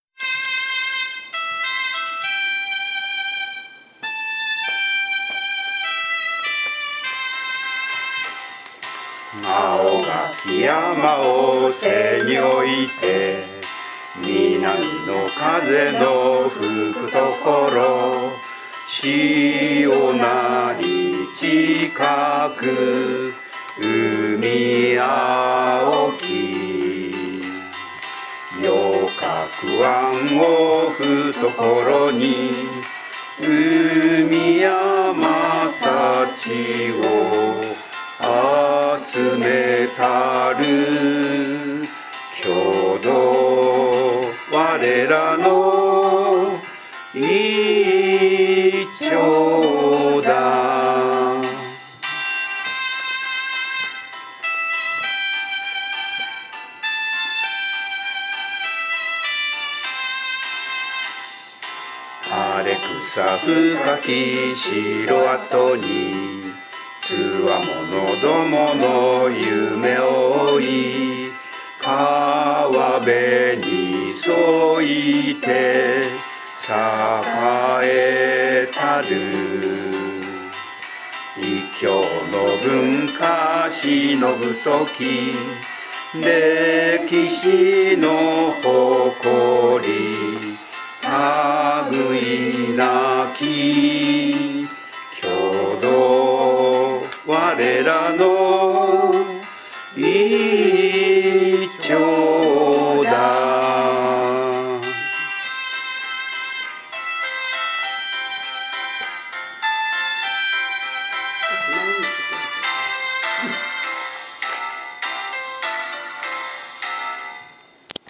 現行政区 旧行政区 No 中学校名 校歌楽譜・歌詞・概要 校歌音源（歌・伴奏）  備考
itchodachu_kouka.mp3